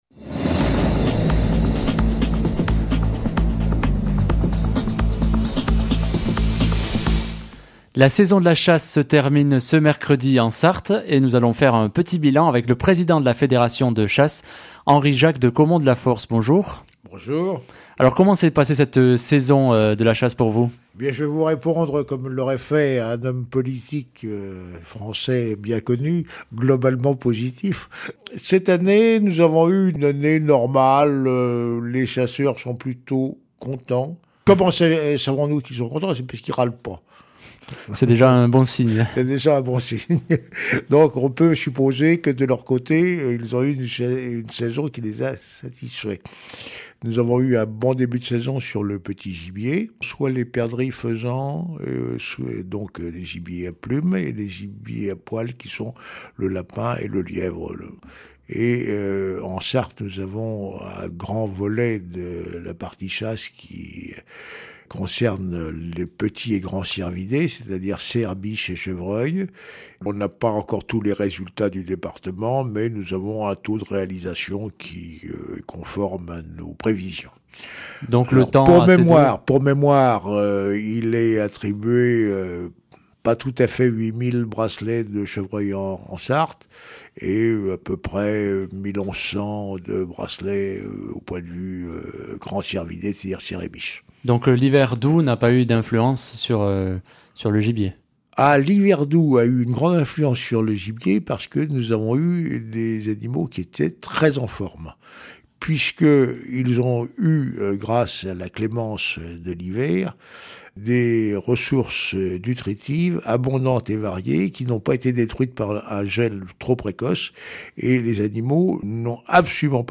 Reportage / documentaire